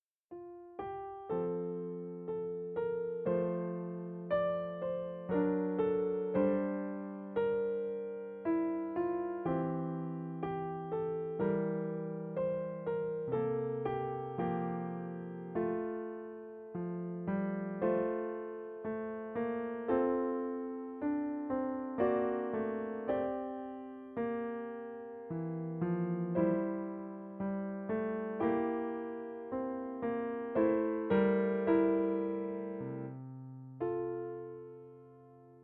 Na horách sejou hrách (piano)
Asi to bylo těžší proto, že se v polovině skladbičky obrací ruce a levá hraje melodii a pravá akordy.